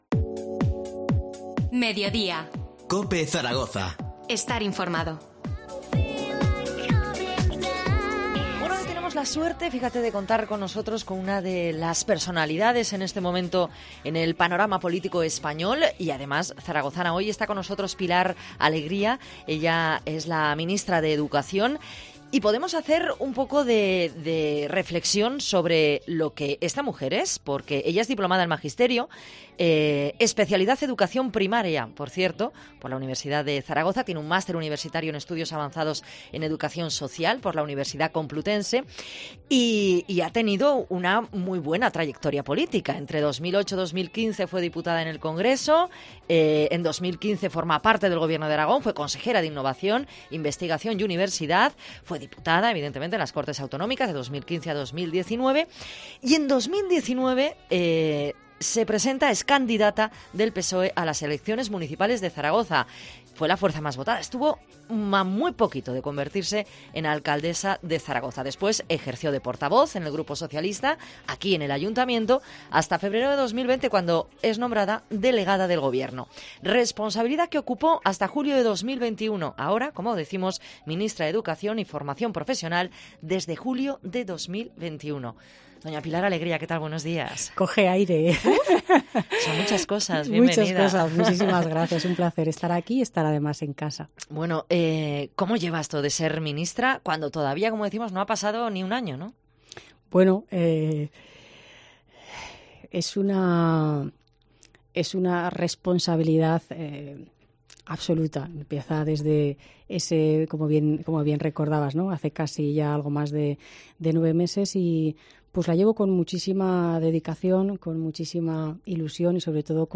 Entrevista a Pilar Alegría, ministra de Educación y Formación Profesional